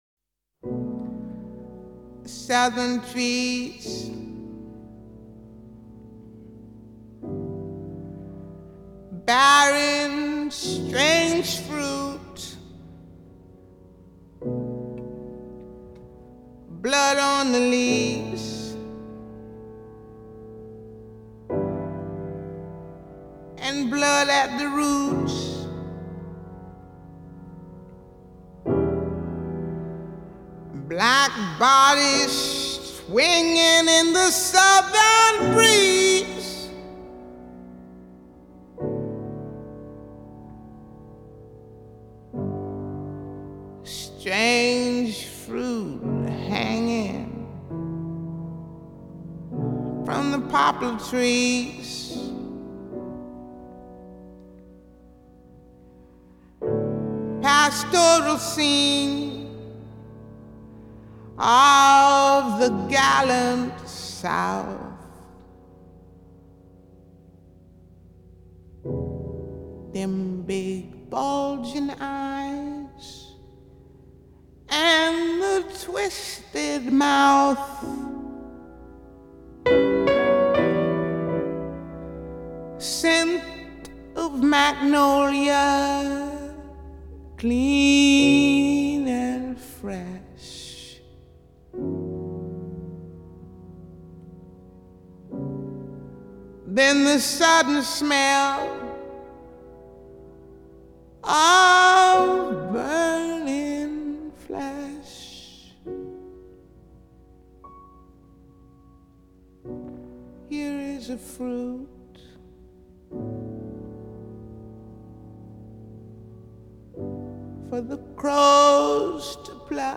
una ballata tragica